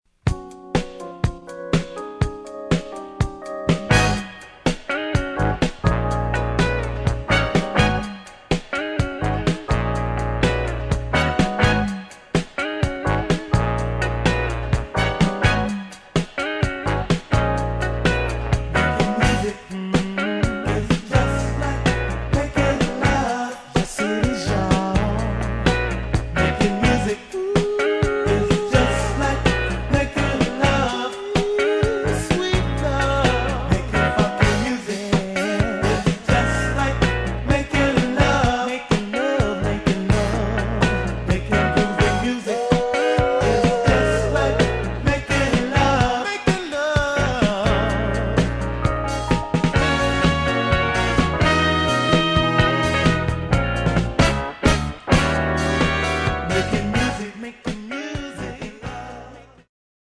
funk groups